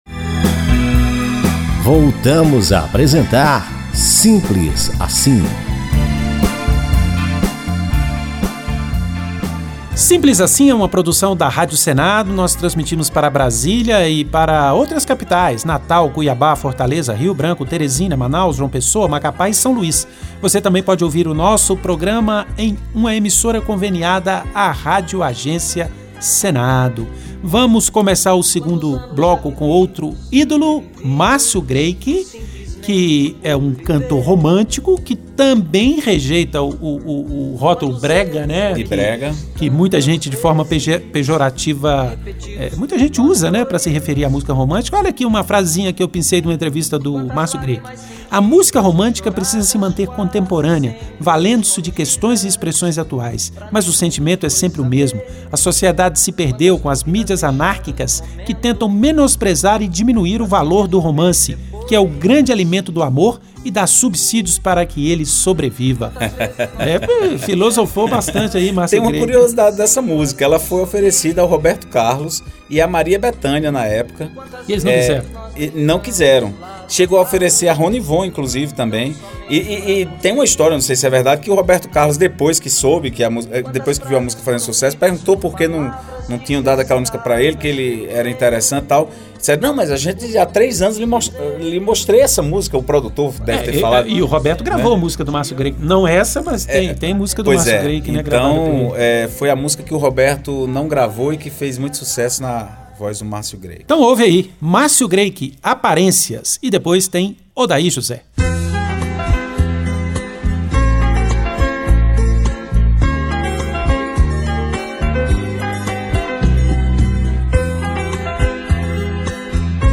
clássicos dos principais cantores populares do Brasil